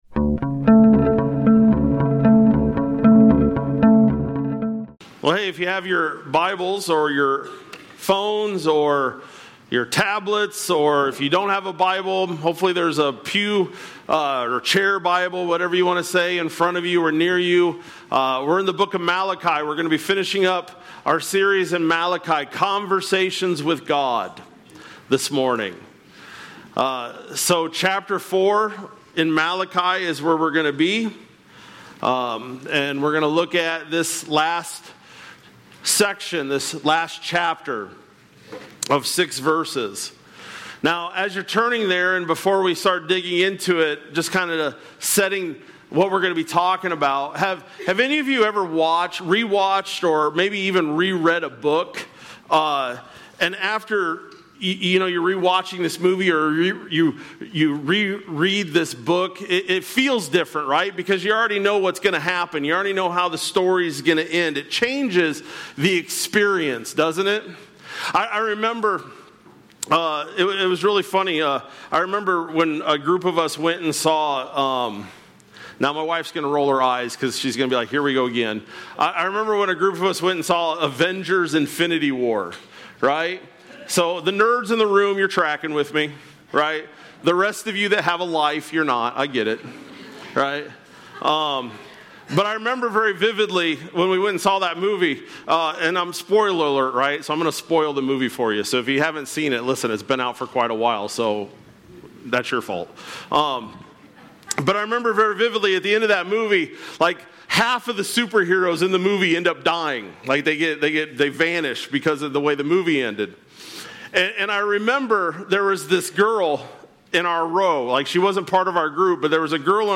Aug-3-25-Sermon-Audio.mp3